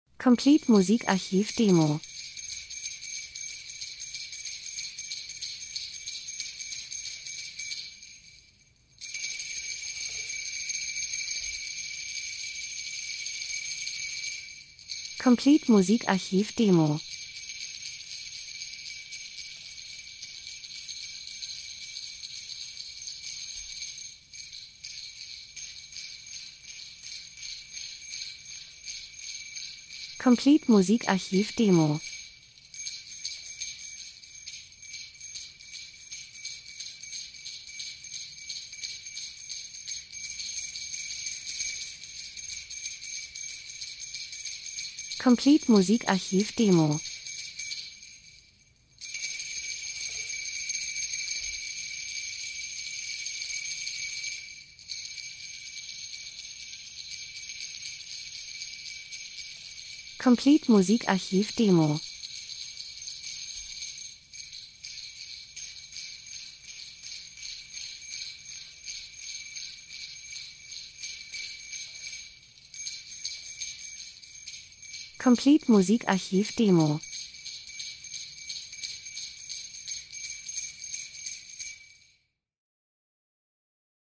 Winter - Schlitten Glöckchen Schlitten weit weg 01:23